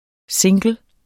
single substantiv, fælleskøn Bøjning -n, -r, -rne Udtale [ ˈseŋgəl ] Oprindelse af engelsk single 'enkelt, eneste' Betydninger 1.